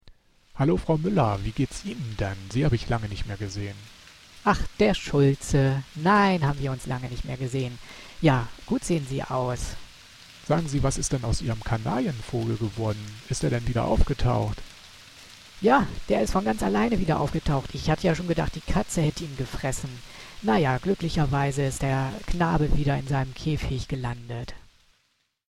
Die wichtigsten Funktionen habe ich Ihnen anhand eines kurzen (und absolut nichtsagenden) Dialogs dargestellt.
Einen Dialog aufnehmen und die Sprache "verändern"
Hintergrundgeräusch einfügen